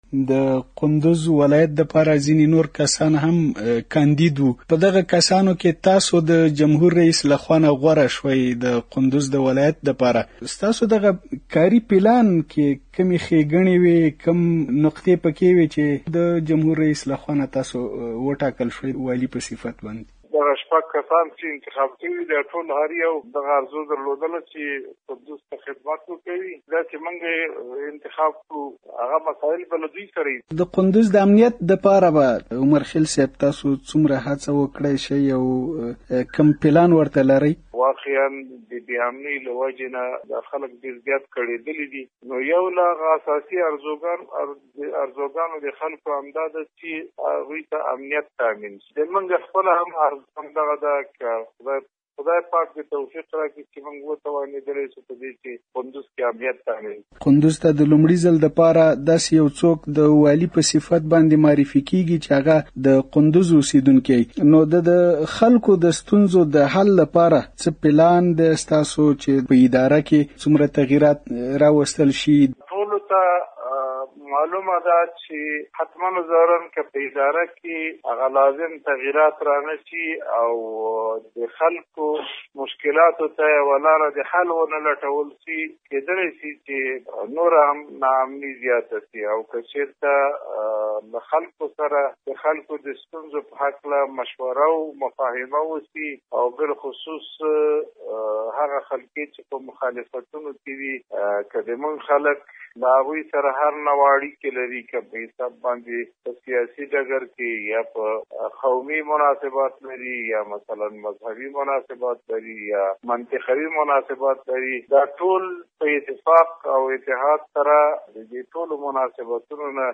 د کندوز والي دا خبرې د امریکا غږ آشنا راډیو سره یوه ځانگړې مرکه کې کړیدي